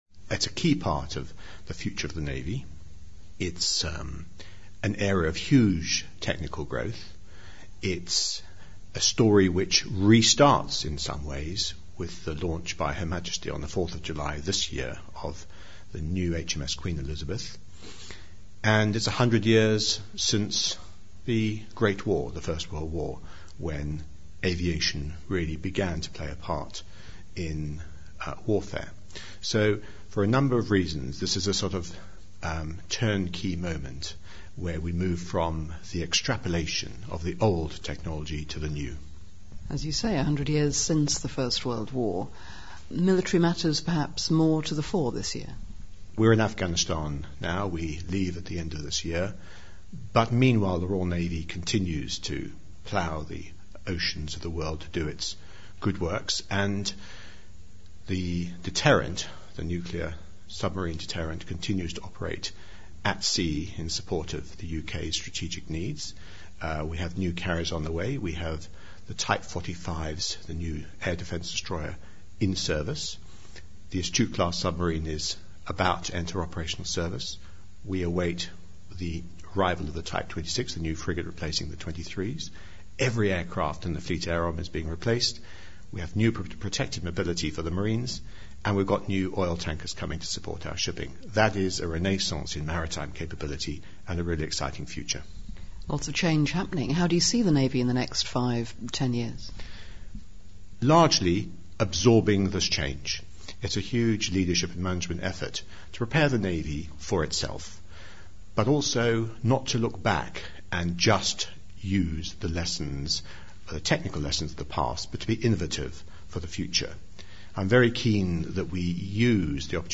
To hear the Admiral talk about the importance of naval aviation, visit the podcast at the top of this page.